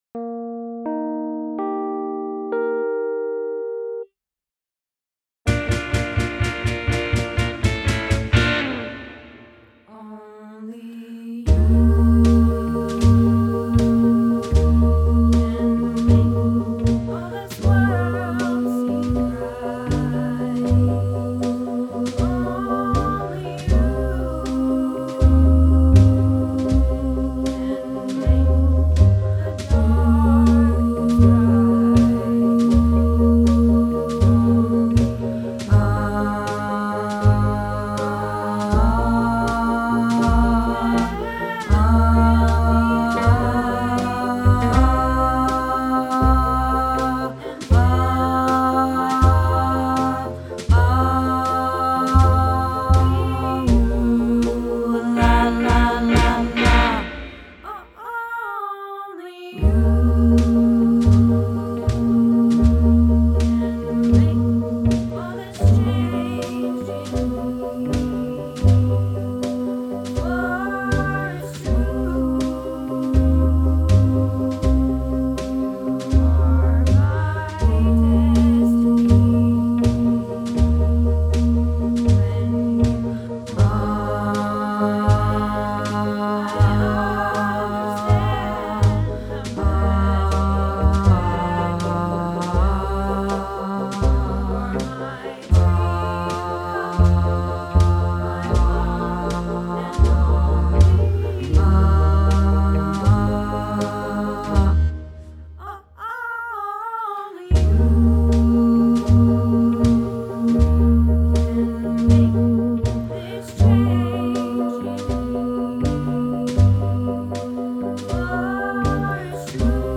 Only You - Bass